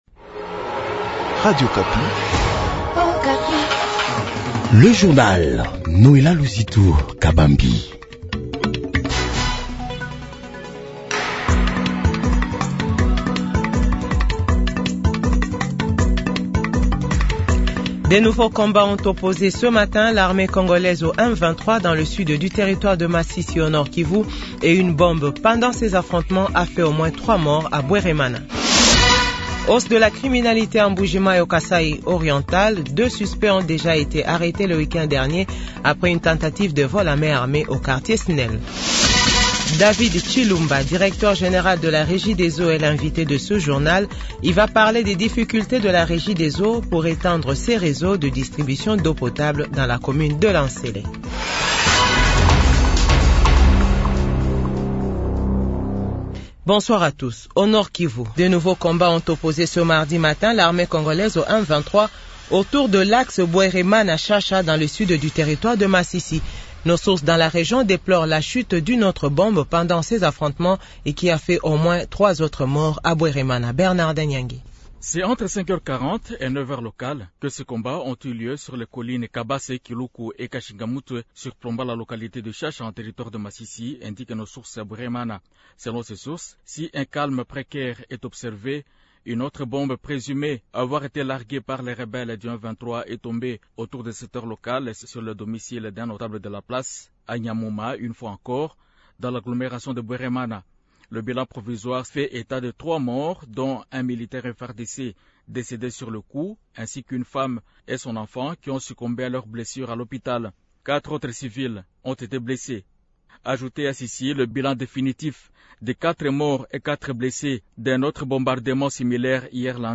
Journal 18H00